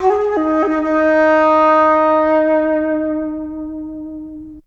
ZG FLUTE 4.wav